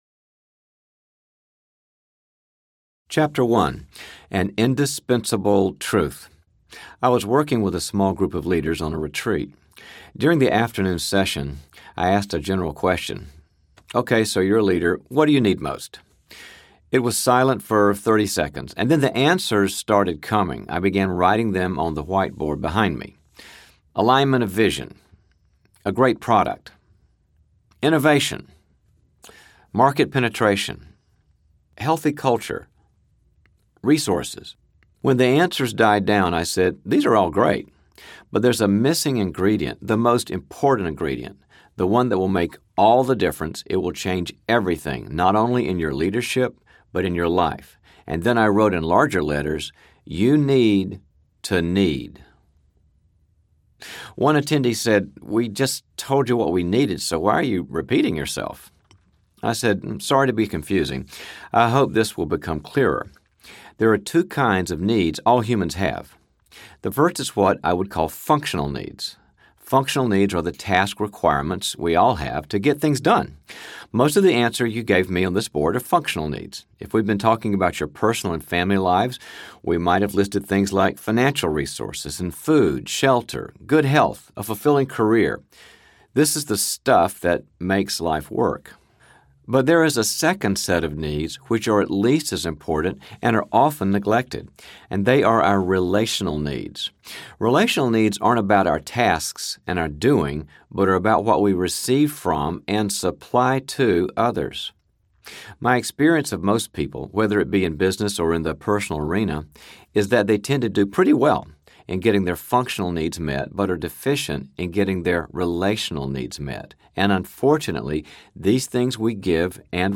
People Fuel Audiobook
6.25 Hrs. – Unabridged